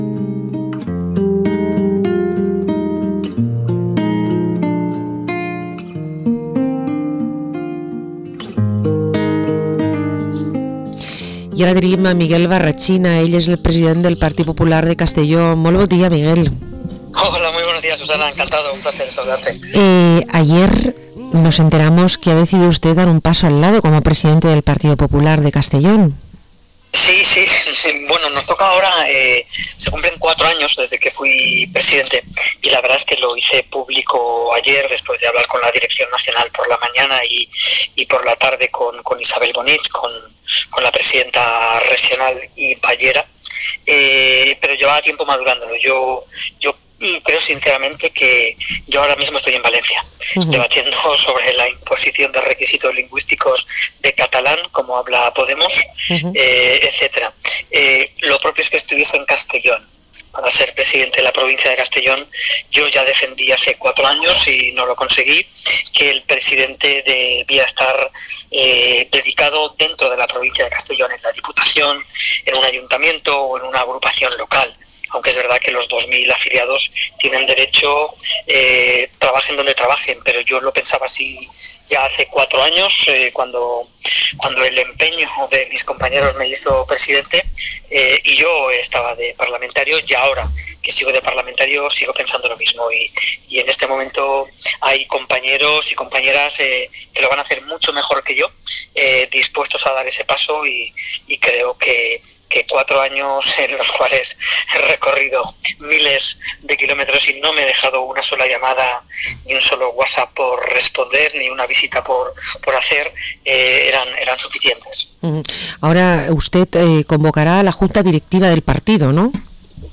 Entrevista al presidente del PP en la provincia de Castellón, Miguel Barrachina